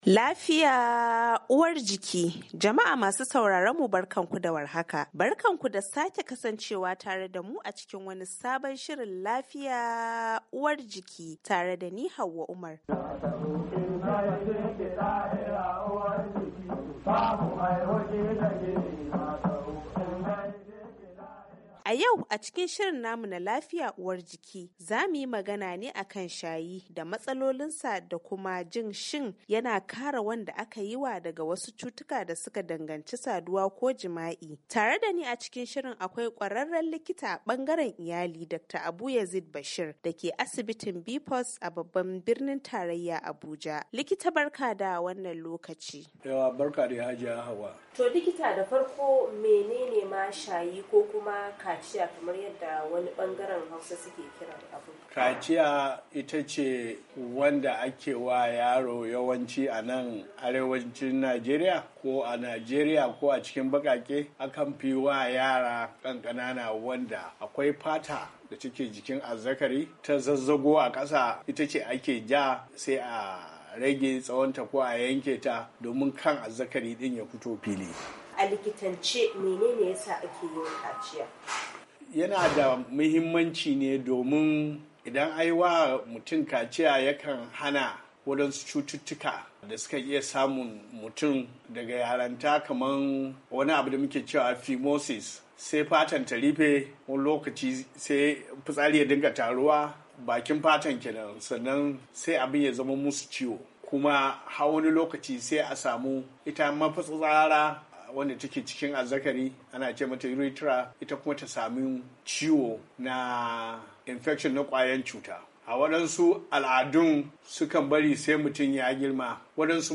LAFIYA UWAR JIKI: Tattaunawa Kan Kaciyar Maza, Satumba 16, 2021